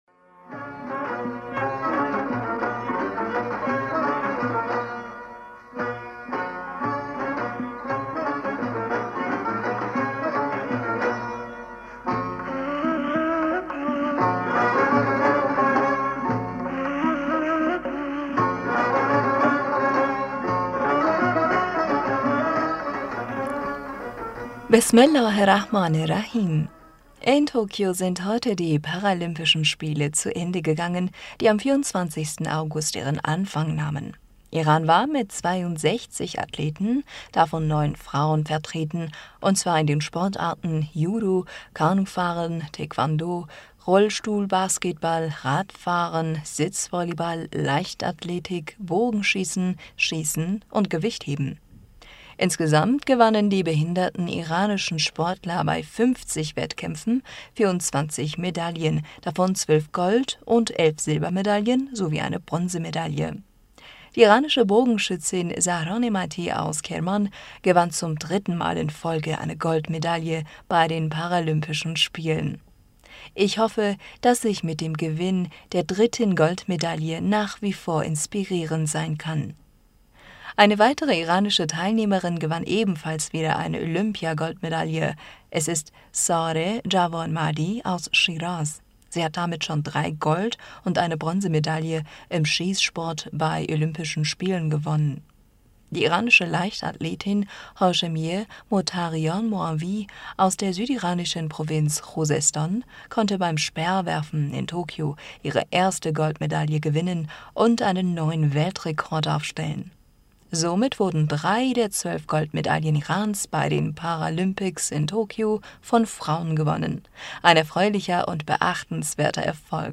Hörerpostsendung am 05. September 2021 Bismillaher rahmaner rahim - In Tokio sind heute die Paralympischen Spiele zu Ende gegangen, die am 24. August ihren Anfang nahmen.